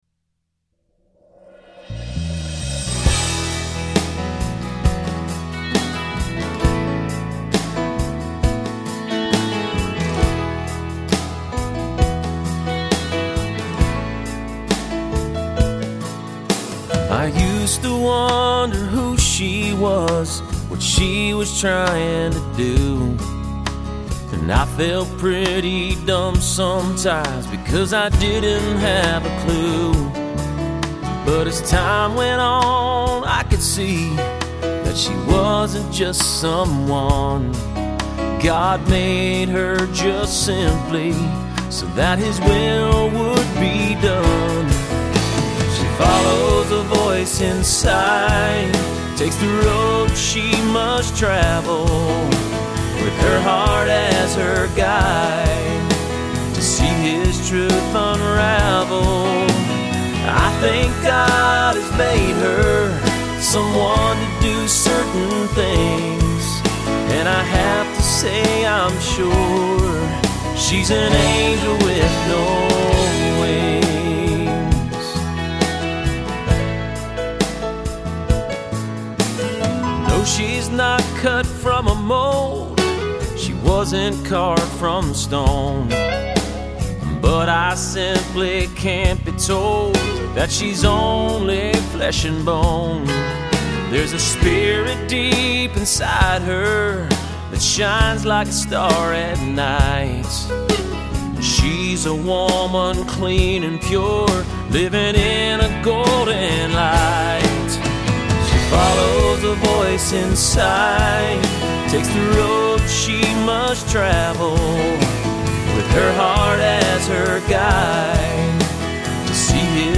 Complete Demo Song, with lyrics and music